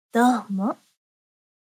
ダウンロード 女性_「どうも」
クール挨拶